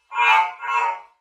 mobs_duck.ogg